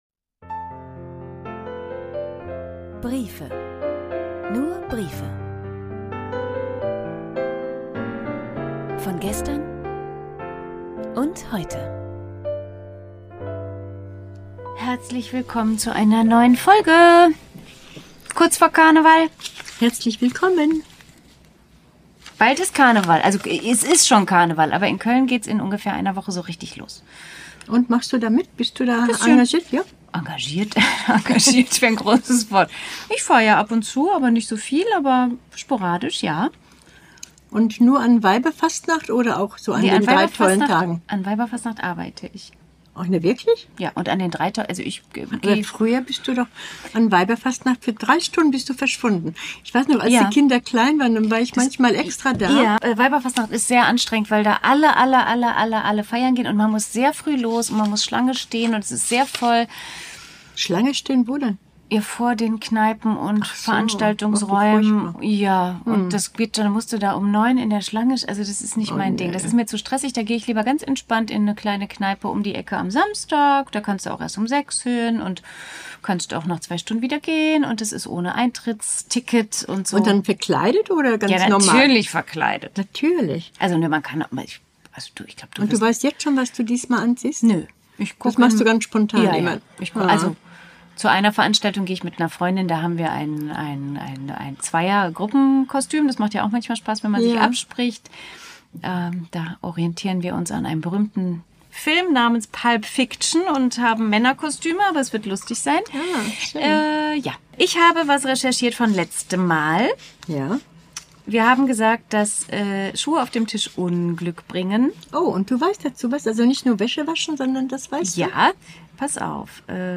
Deine stimme klingt so sanft und schön